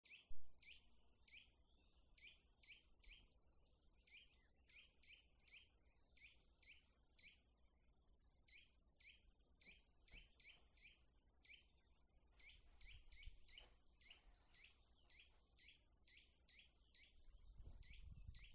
Nuthatch, Sitta europaea
StatusSpecies observed in breeding season in possible nesting habitat